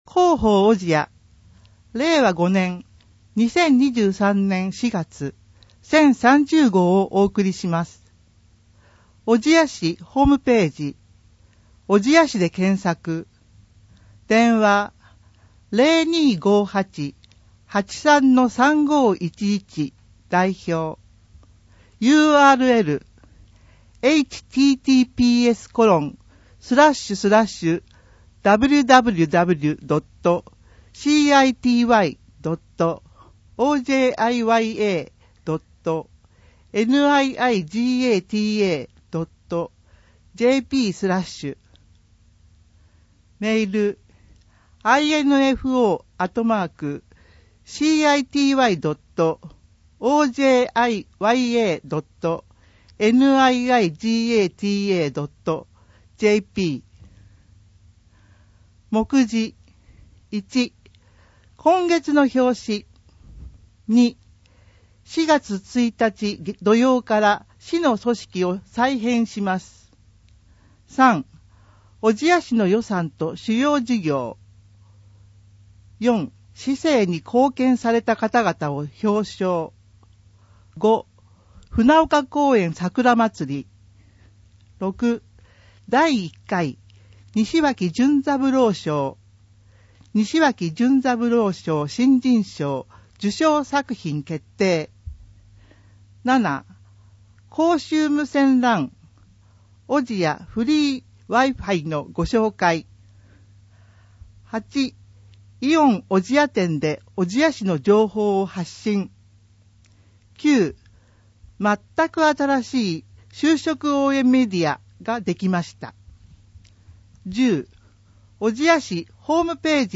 令和5年度広報おぢやの音声訳（MP3） - 小千谷市ホームページ
現在、広報おぢや、社協だより、小千谷新聞の音声訳を行い、希望する方へ無料で音声訳CDをお届けしています。